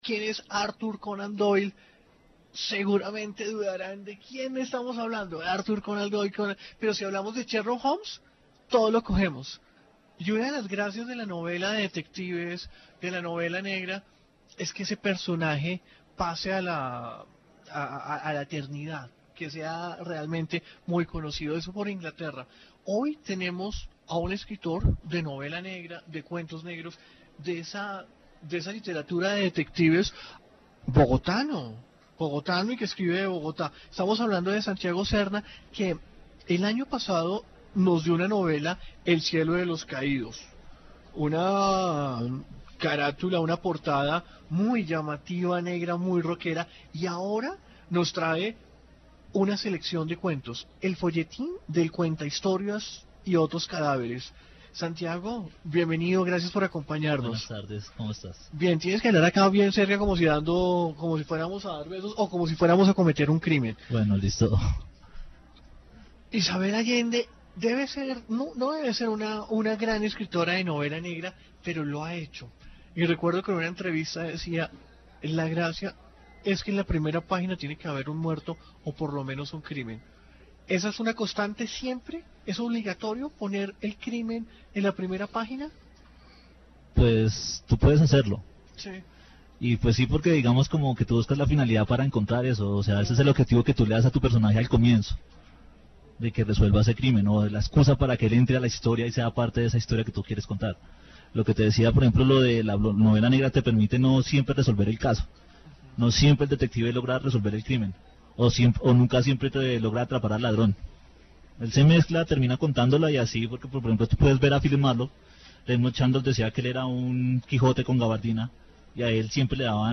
Informe radial
Programas de radio